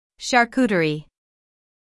IPA: /ʃɑːr.ˈkuː.tər.iː/.
Syllables: Char · CU · te · rie
charcuterie-us.mp3